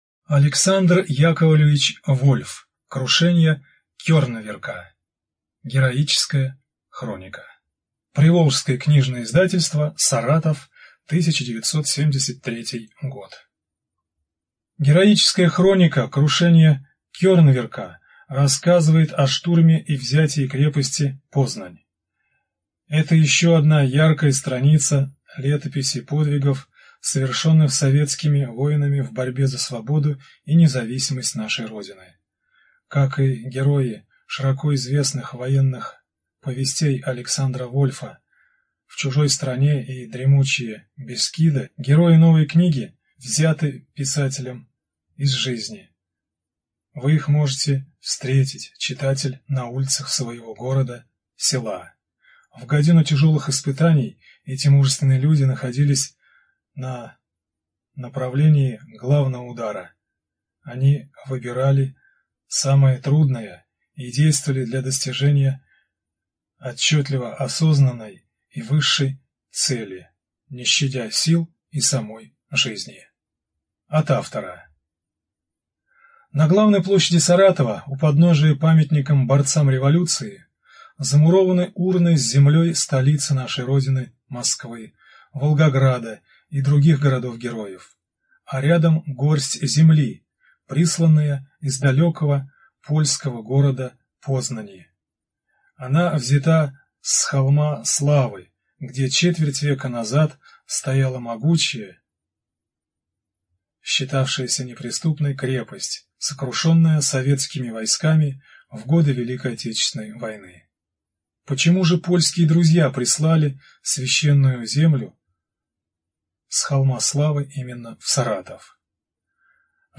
Студия звукозаписиСаратовская областная библиотека для слепых